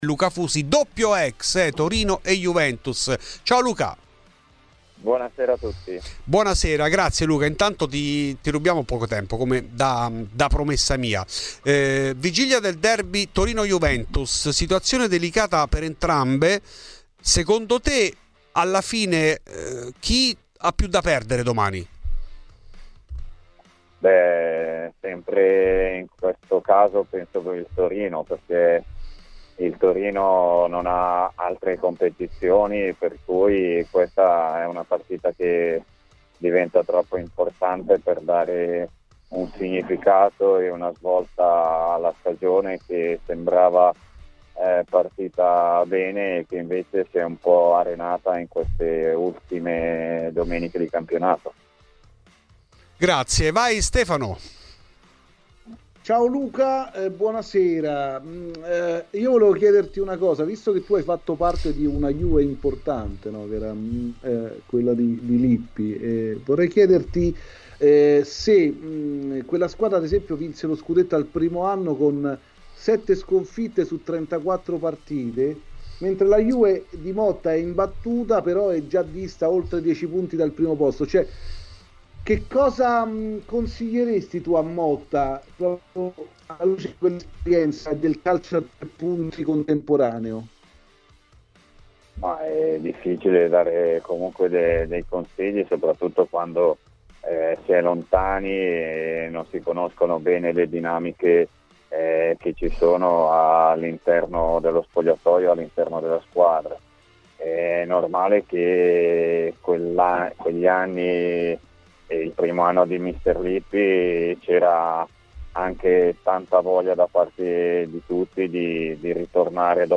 Il doppio ex del derby della Mole Luca Fusi è stato ospite di Radio Bianconera.